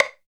87 STICK  -L.wav